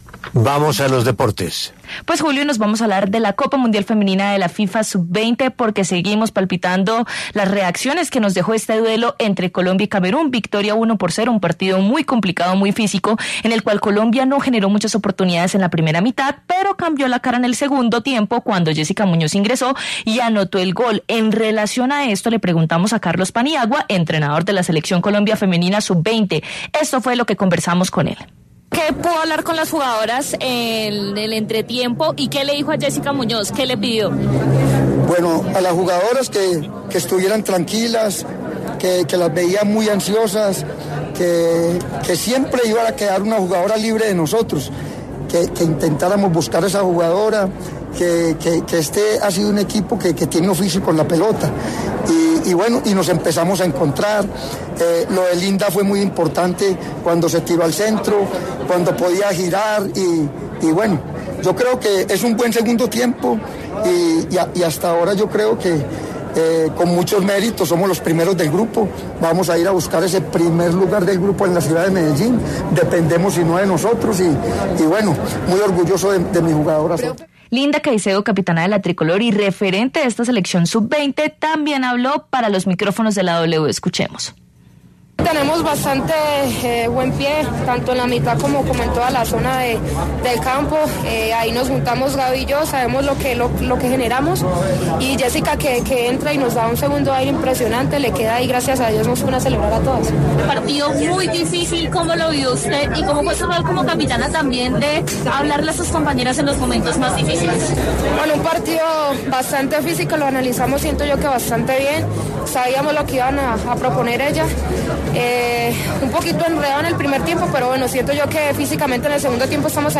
El entrenador de la Selección Colombia Femenina Sub-20 y Linda Caicedo pasaron por los micrófonos de W Radio después de la victoria 1-0 ante Camerún.